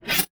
Select Scifi Tab 8.wav